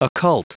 Prononciation du mot occult en anglais (fichier audio)
Prononciation du mot : occult